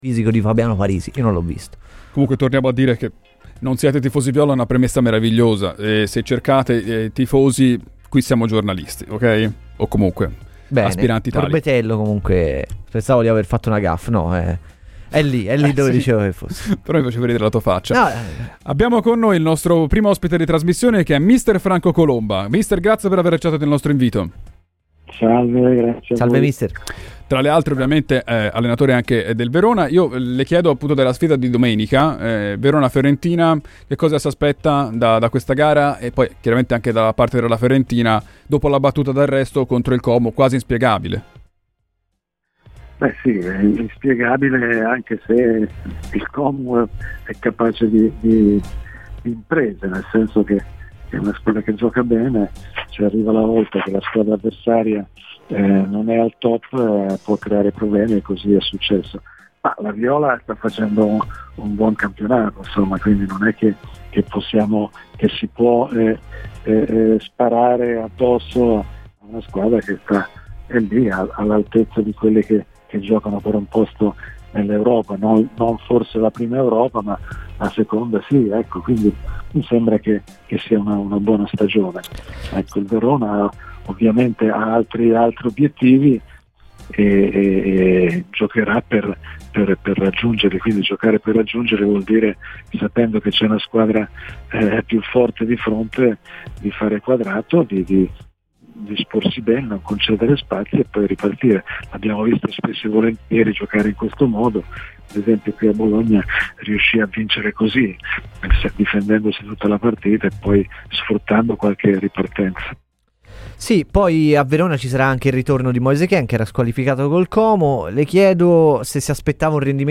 Franco Colomba, allenatore di lungo corso che in carriera si è seduto anche sulla panchina dell'Hellas Verona, in avvicinamento del match tra gli scaligeri e la Fiorentina, è intervenuto ai microfoni di Radio FirenzeViola, durante "I tempi Supplementari", queste le sue parole: "Domenica sconfitta inspiegabile anche se il Como è capace di imprese, nel senso che è una squadra che gioca bene, e può arrivare la svolta se la squadra avversaria non è al top,e così è successo.